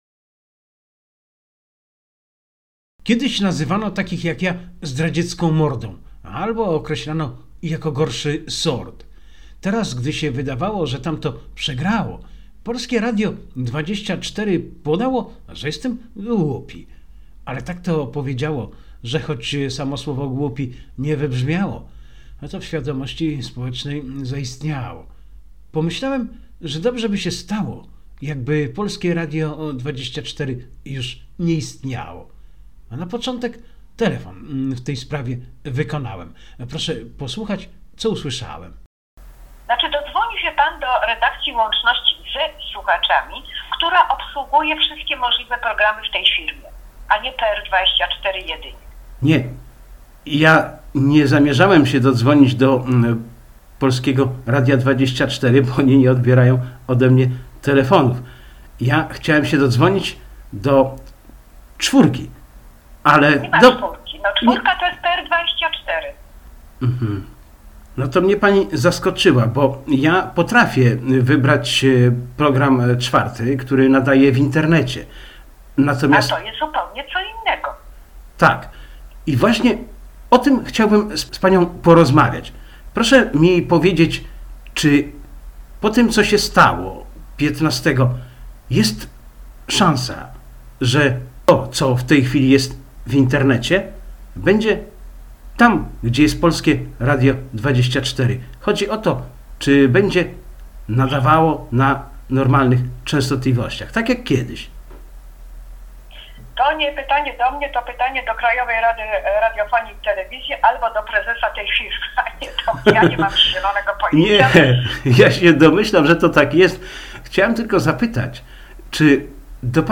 Wywiad-Polskie-Radio-24.mp3